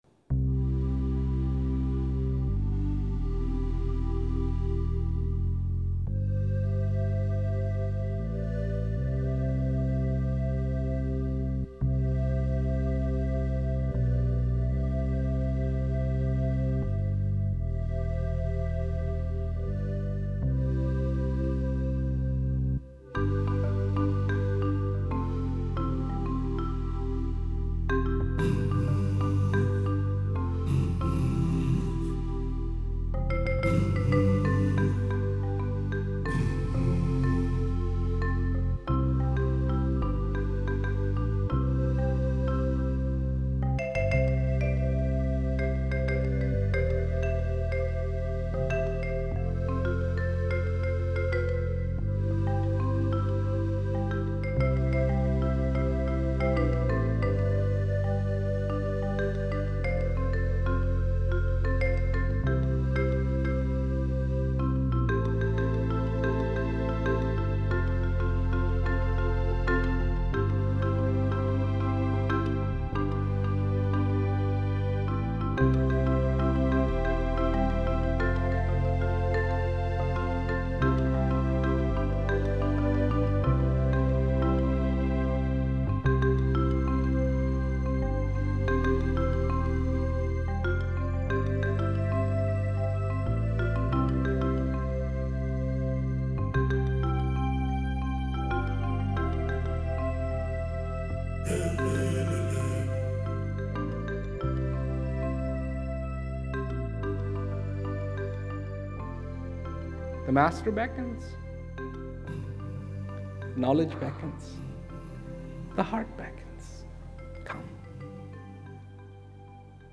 It was a combination of songs recorded by some of his followers and two sections of Rawat's speeches with background music that attempts to enhance Rawat's message.
Mp3 copies of these excerpts are recorded at high quality (256Kbps) to ensure no whispered nuance or frenzied climax is missed.